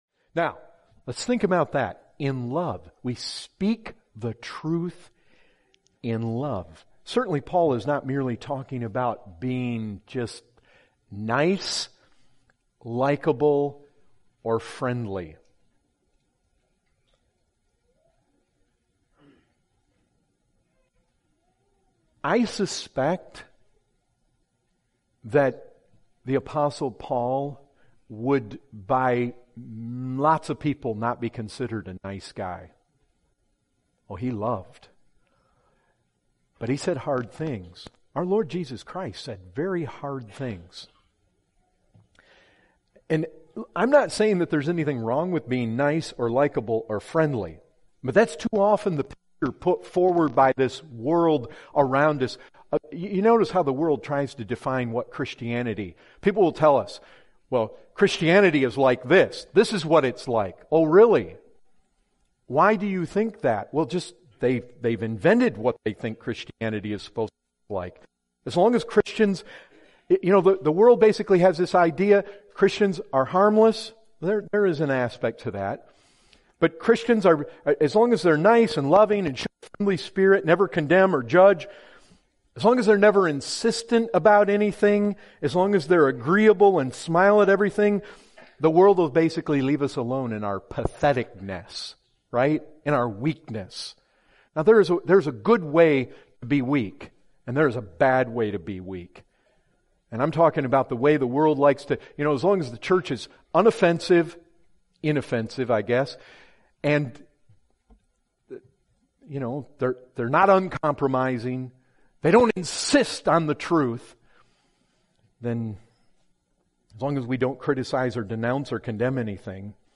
Excerpt is taken from the full sermon, “ Speaking the Truth in Love “.